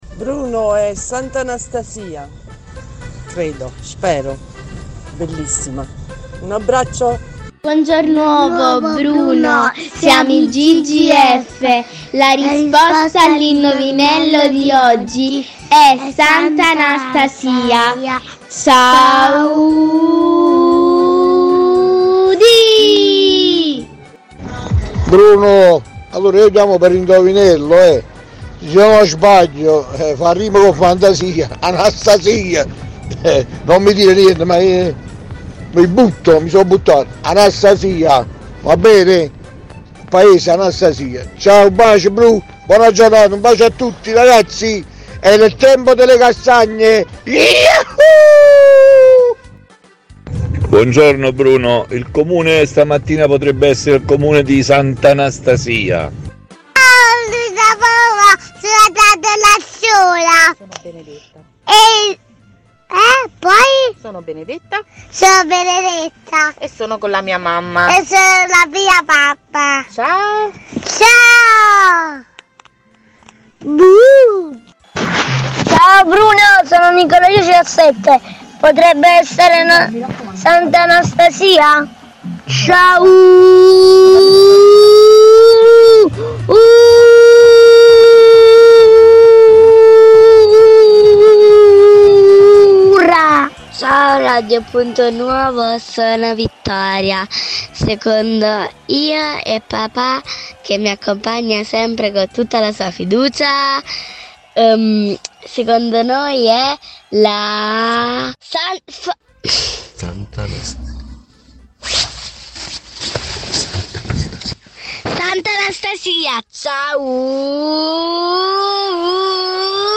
RIASCOLTA LE RISPOSTE DEGLI ASCOLTATORI